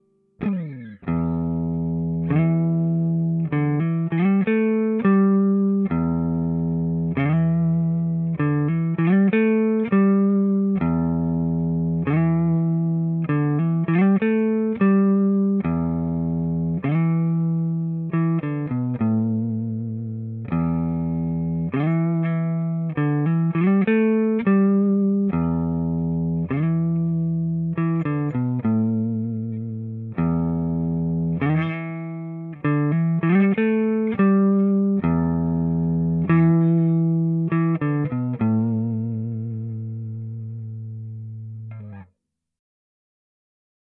钢琴前奏摇滚乐循环播放 1 k
描述：摇滚或蓝调的前奏
Tag: 115 bpm Rock Loops Piano Loops 3.70 MB wav Key : Unknown